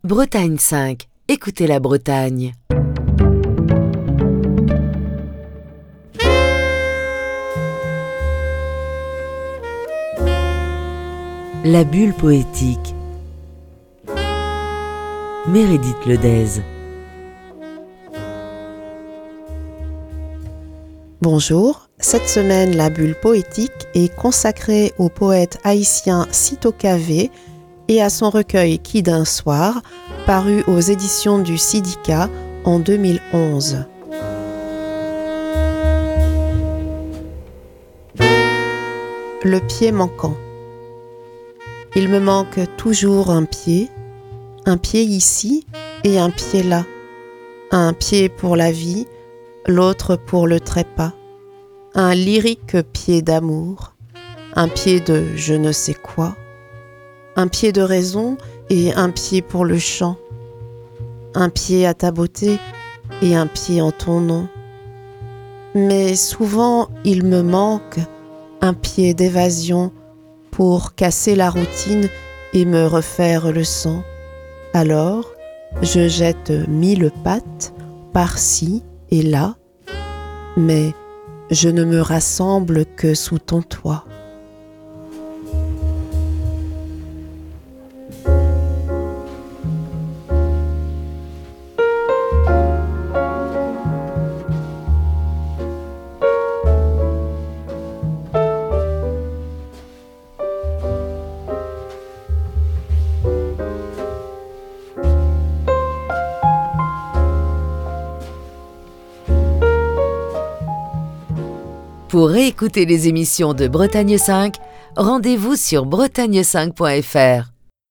lit quelques poèmes du poète haïtien Syto Cavé